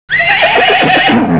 Cŕŕn Zaerdžanie koňa 0:02